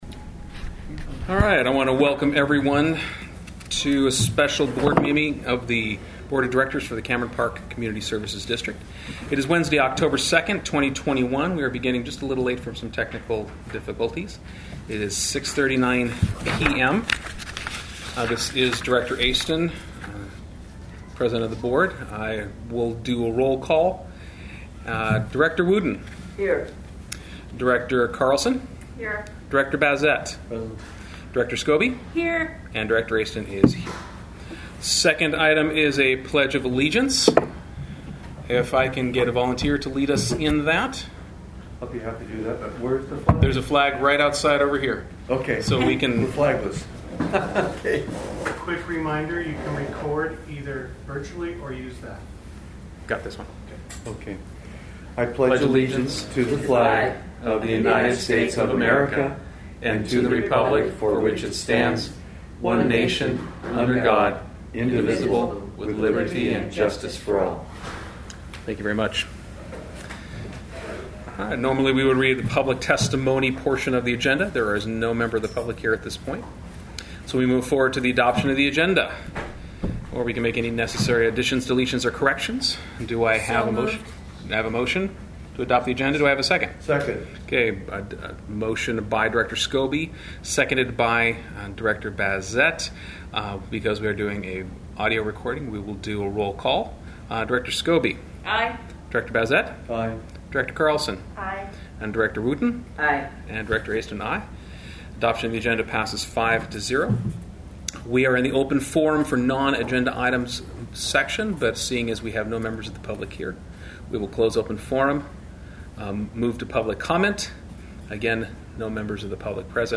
Board of Directors Meeting